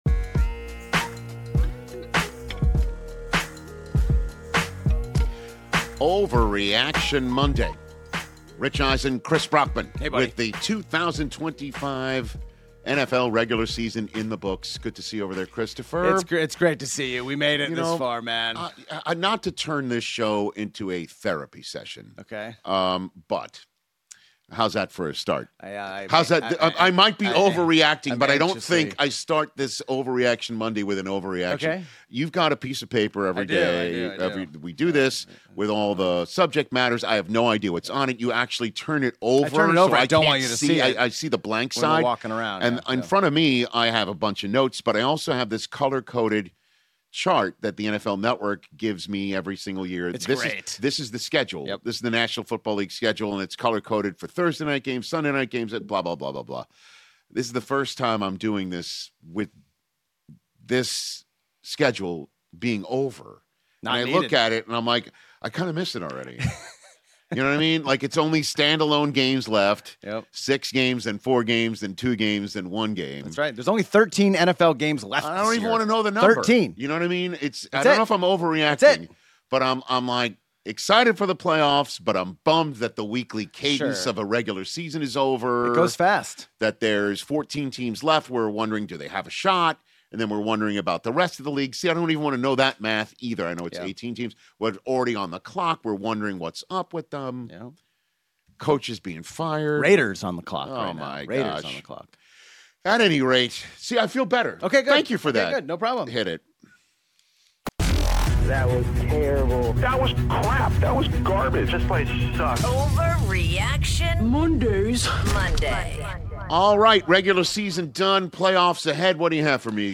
debating the latest in the NFL